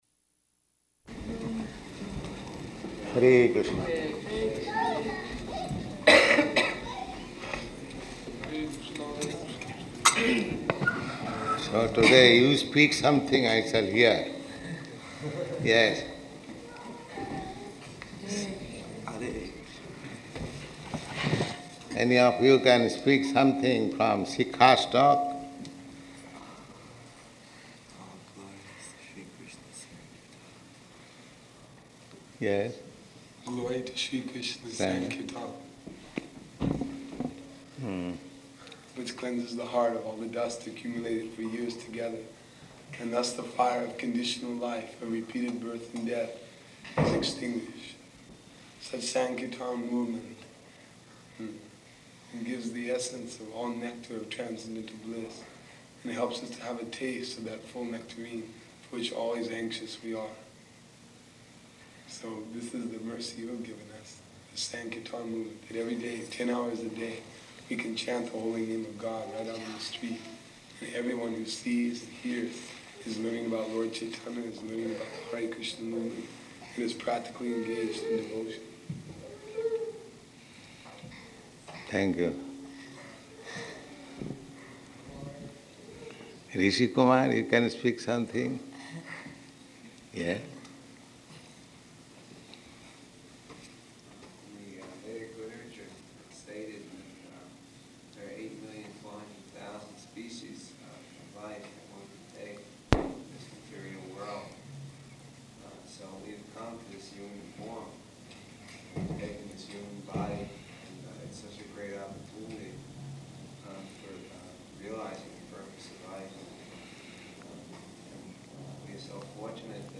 Śrīla Prabhupāda and Devotees Speak --:-- --:-- Type: Lectures and Addresses Dated: April 9th 1969 Location: New York Audio file: 690409LE-NEW_YORK.mp3 Prabhupāda: Hare Kṛṣṇa.